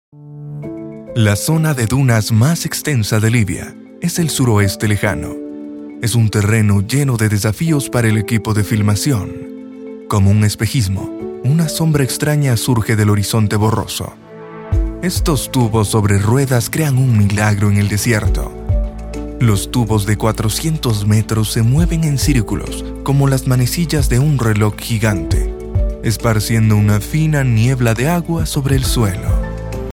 Échantillons de voix natifs
Documentaires